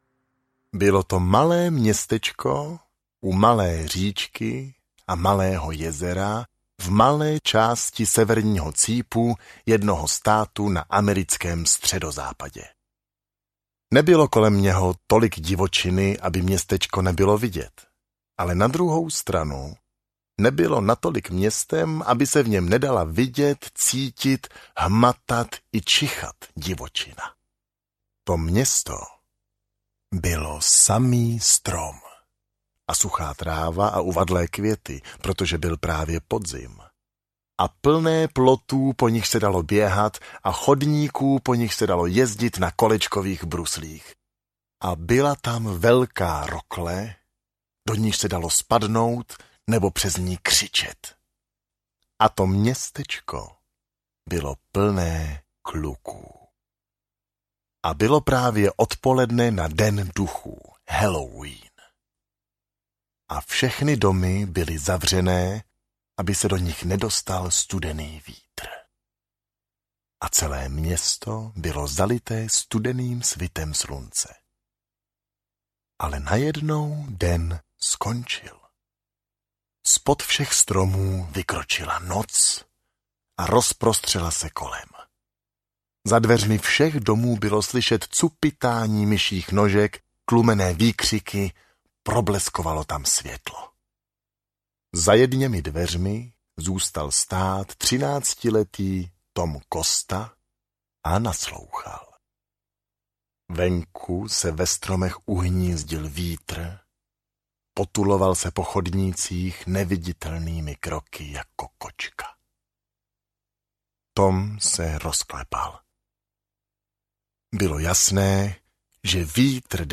Strom duchů audiokniha
Ukázka z knihy